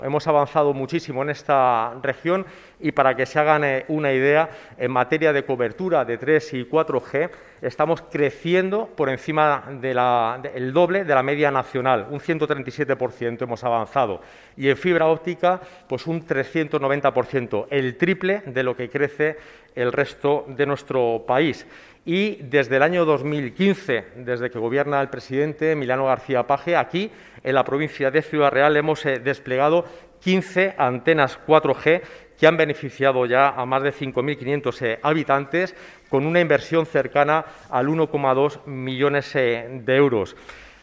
Lo ha hecho durante la rueda de prensa que ha tenido lugar en la Diputación de Ciudad Real, y en la que ha comparecido junto al presidente de esta institución provincial, José Manuel Caballero; la alcaldesa de la capital, Pilar Zamora; el viceconsejero de Medio Ambiente, Fernando Marchán; el director general de Cohesión Territorial, Alipio García; la delegada de la Junta en Ciudad Real, Carmen Teresa Olmedo; y el delegado provincial de Desarrollo Sostenible, Fausto Marín.